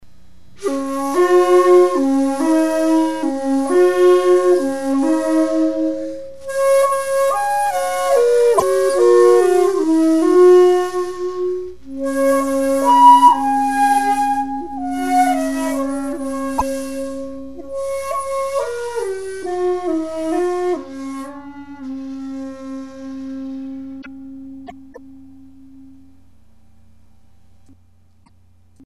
It covers a wide spectrum of tone colors. Mysterious yet direct. Subtle yet with presence.
Its tone is fashioned after some of the finer vintage jinashi flutes I've had the opportunity to play and study.